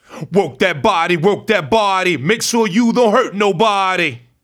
RAPHRASE04.wav